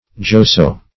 joso - definition of joso - synonyms, pronunciation, spelling from Free Dictionary Search Result for " joso" : The Collaborative International Dictionary of English v.0.48: Joso \Jo"so\, n. (Zool.)